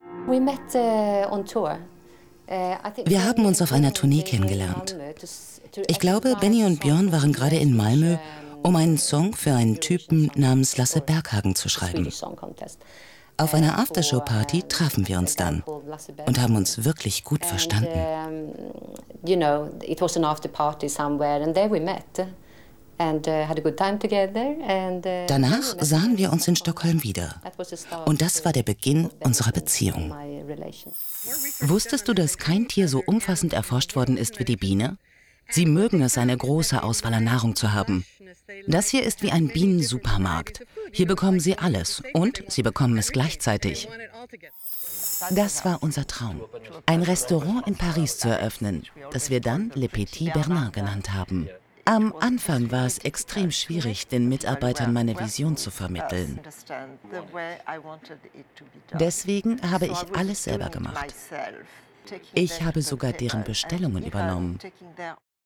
Warme, angenehme Stimme.
Strahlt viel Ruhe aus.
Sprechprobe: Sonstiges (Muttersprache):
female voice over artist